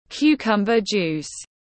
Nước ép dưa chuột tiếng anh gọi là cucumber juice, phiên âm tiếng anh đọc là /ˈkjuː.kʌm.bər ˌdʒuːs/
Cucumber juice /ˈkjuː.kʌm.bər ˌdʒuːs/